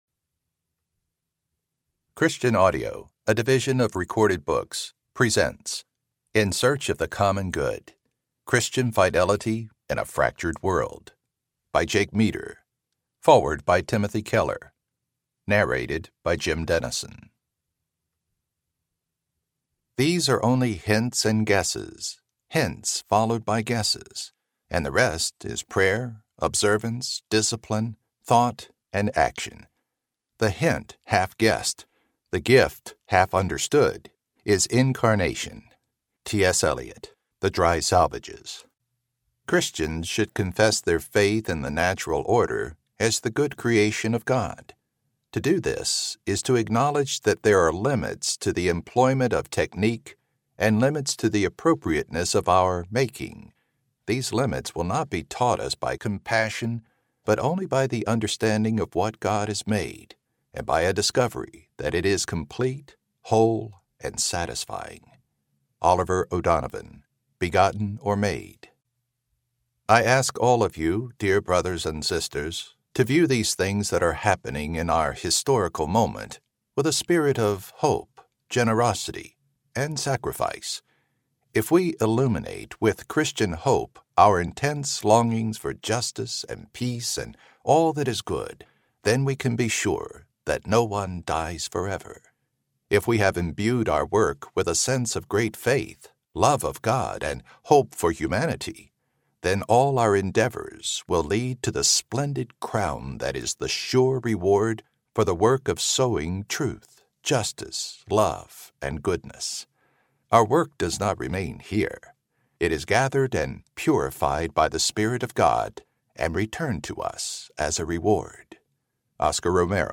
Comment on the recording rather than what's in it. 7.91 – Unabridged